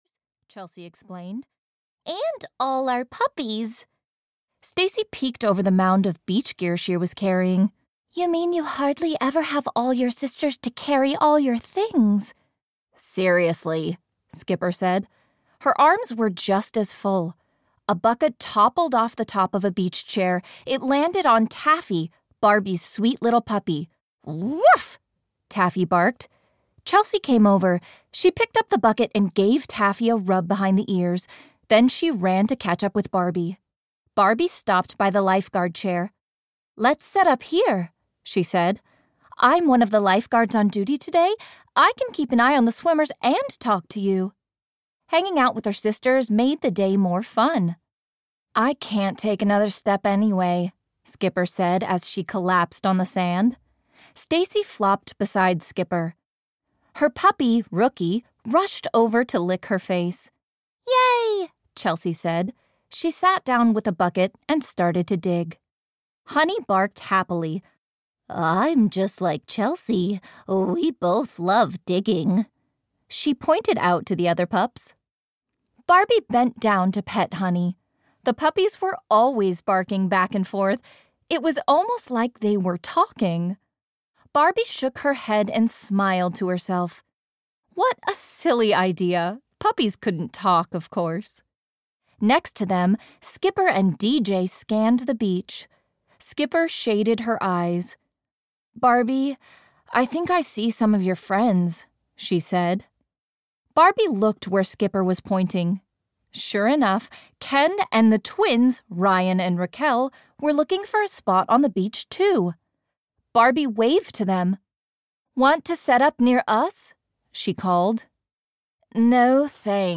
Barbie - Sisters Mystery Club 1 - The Beach Bandit (EN) audiokniha
Ukázka z knihy